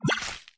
Appear_Wild_Sound.mp3